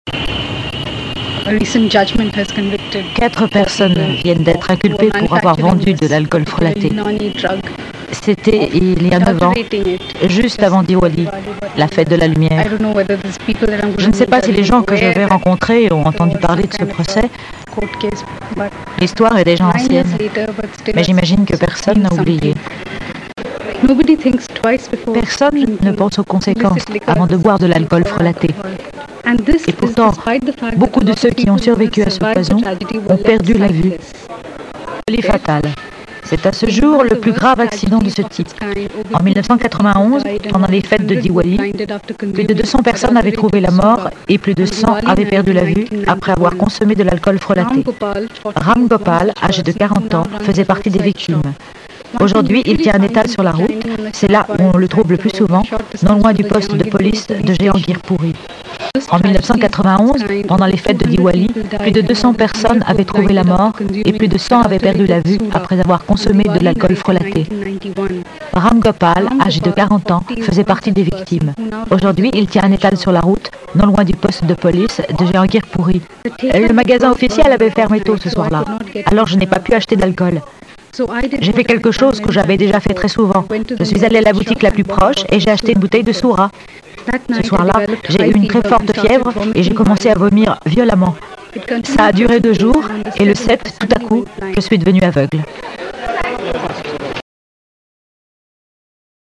voice over pour France3
Voix off